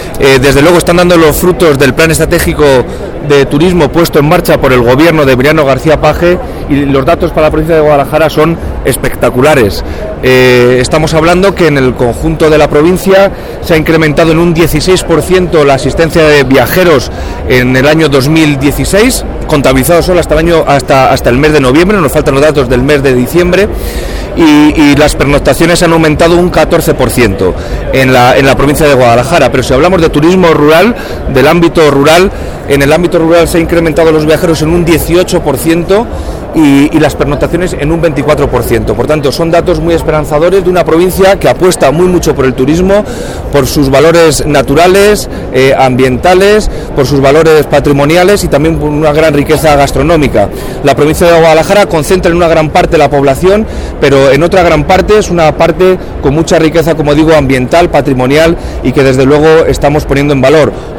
El delegado de la Junta en Guadalajara, Alberto Rojo, sobre el Día de Guadalajara en FITUR